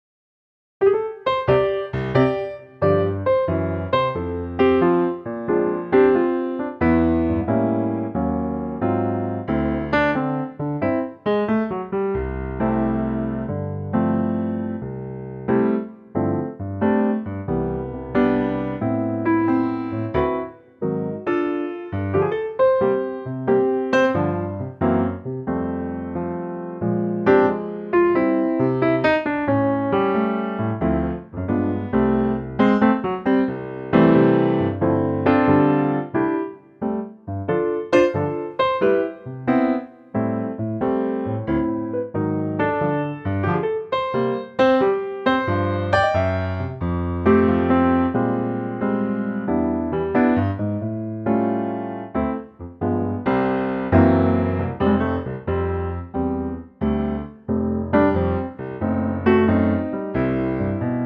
Unique Backing Tracks
key - C - vocal range - C to D